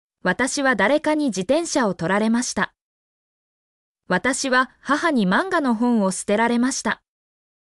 mp3-output-ttsfreedotcom-4_25POyYOM.mp3